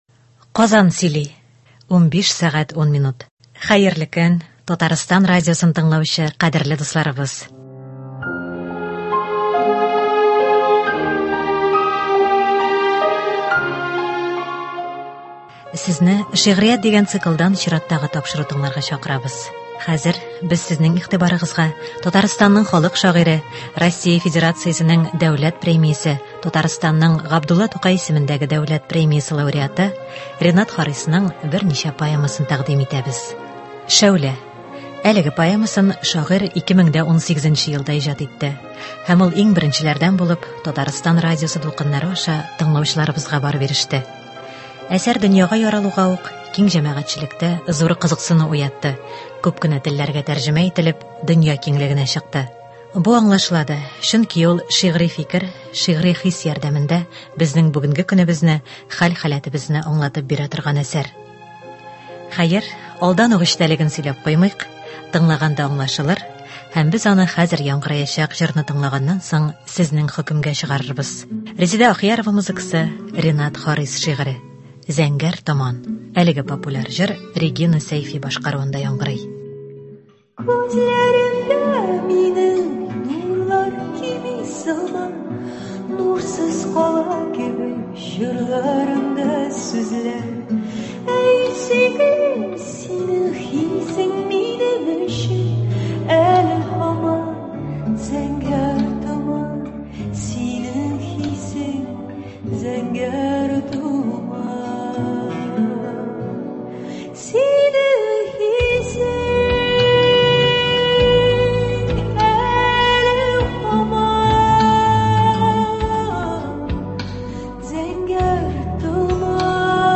Хәзер без сезнең игътибарыгызга Татарстанның халык шагыйре, Россия Федерациясенең дәүләт премиясе, Татарстанның Габдулла Тукай исемендәге дәүләт премиясе лауреаты Ренат Харисның “Шәүлә” һәм “Гармунчы” диг”н поэмаларын тыңларга чакырабыз. Аларны шагыйрь үзе укый. Рөстәм Яхинның “Оныта алмыйм”, Әнвәр Бакировның “Кемгә сөйлим серләремне?” һәм халкыбызның “Озату”, “Ай, былбылым”, “Карурман”, “Тәзкирә”, “Авыл” көйләрен хромкада шагыйрь үзе уйный.